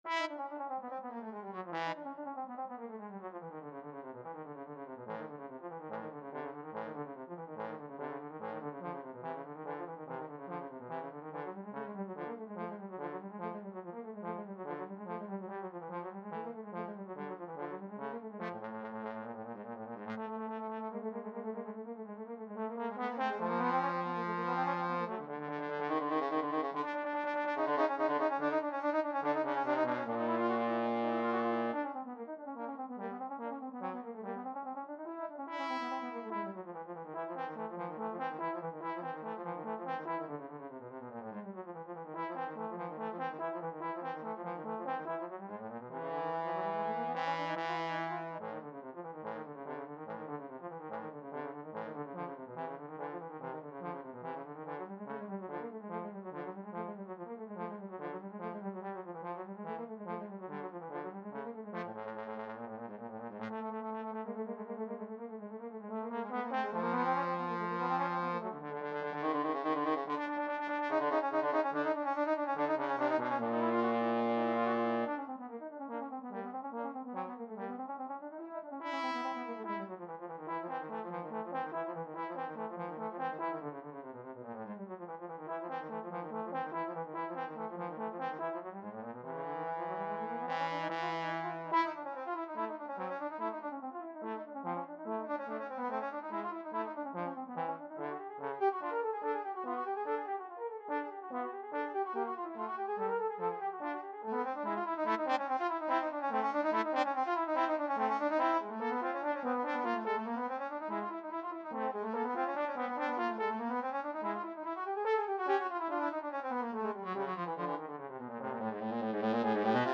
Free Sheet music for Trombone Duet
Vivace = 144 (View more music marked Vivace)
2/4 (View more 2/4 Music)
A minor (Sounding Pitch) (View more A minor Music for Trombone Duet )
Classical (View more Classical Trombone Duet Music)